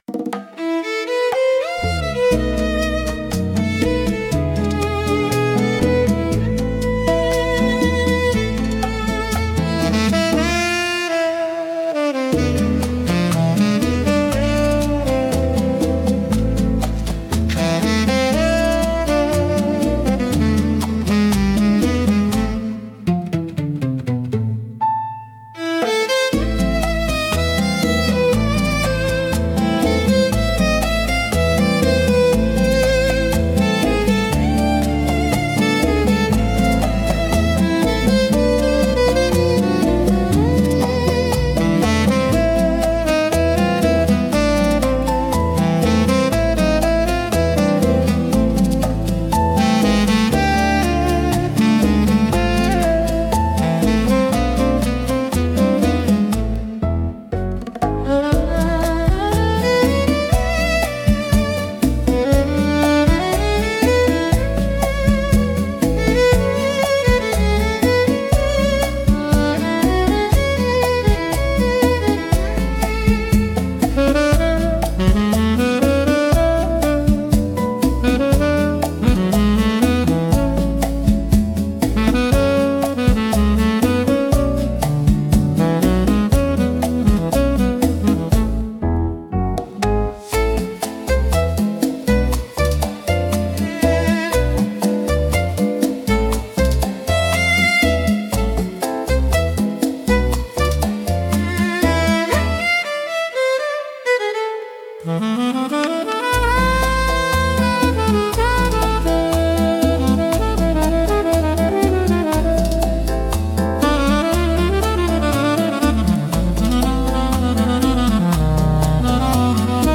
instrumental 7